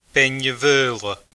Pronunciation[peɲəˈvɤːlˠ̪ə]